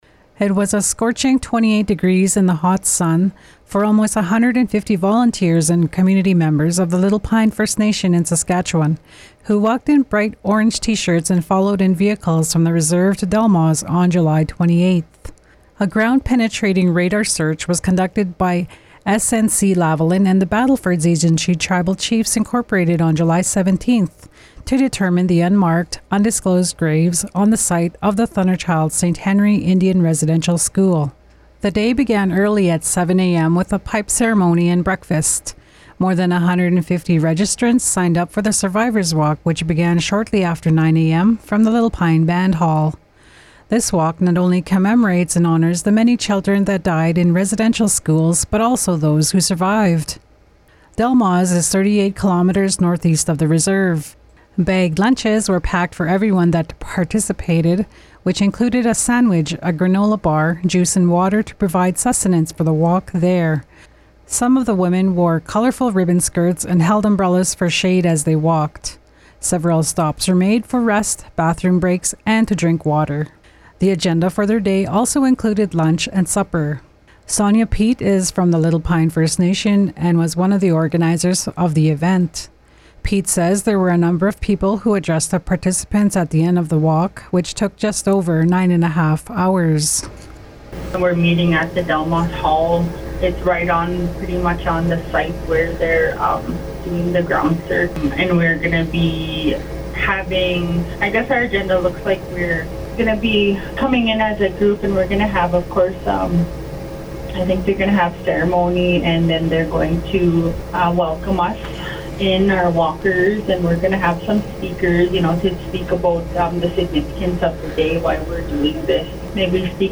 Radio_doc_Survivorswalk_BK.mp3